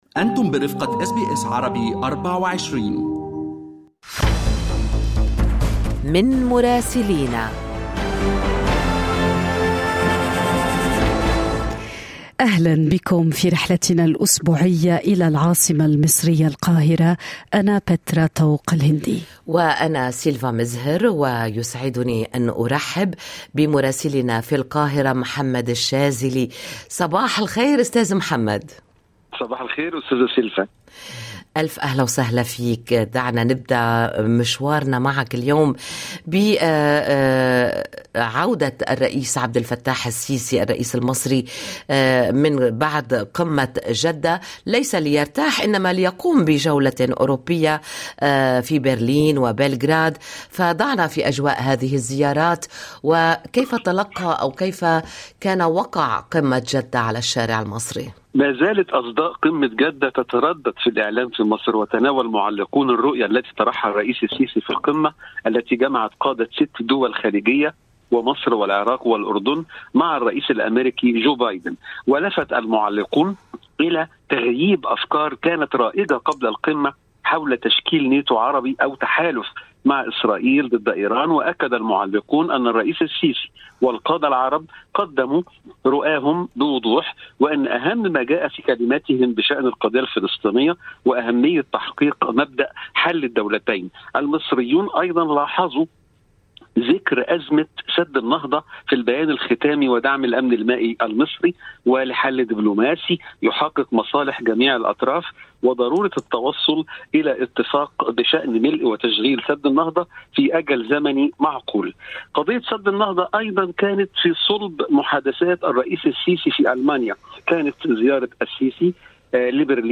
من مراسلينا: أخبار مصر في أسبوع 20/7/2022